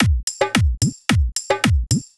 106 BPM Beat Loops Download